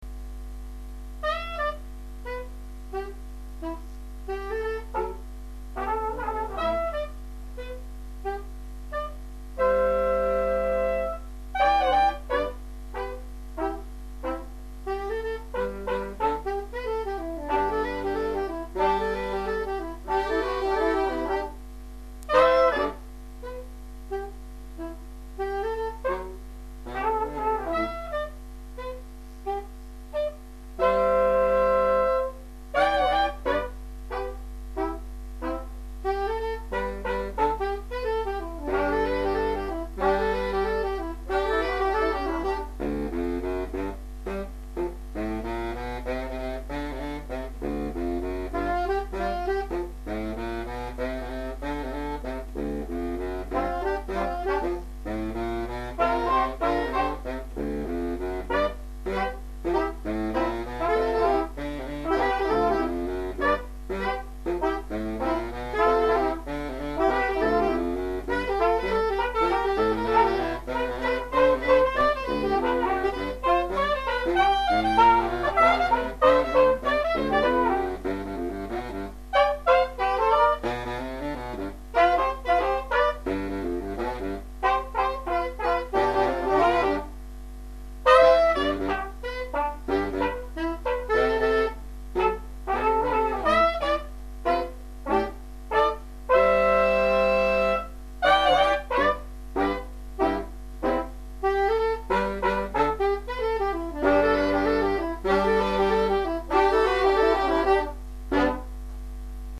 ss, bars, tp/flh, !perf
· Genre (Stil): Jazz